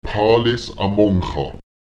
Beachten Sie, dass in solchen Lehnwörtern auch die Betonung übernommen wird: